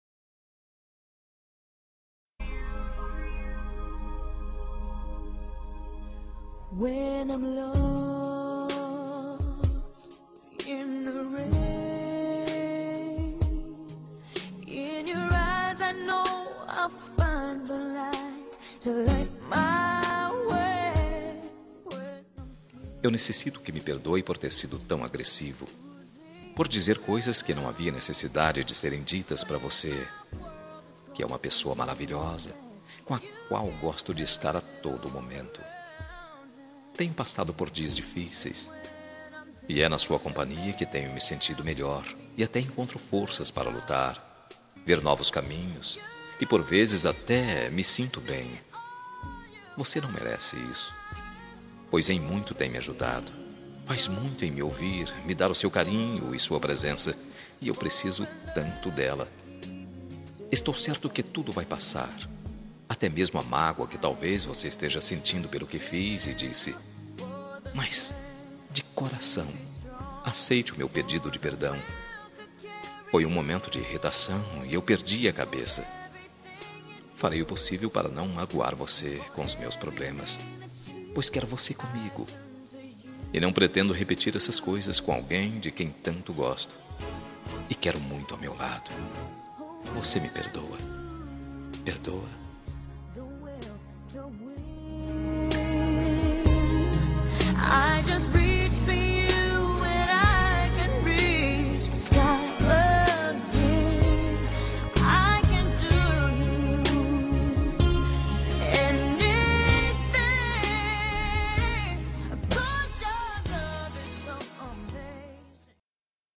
Telemensagem de Desculpas – Voz Masculina – Cód: 201815 – Fui agressivo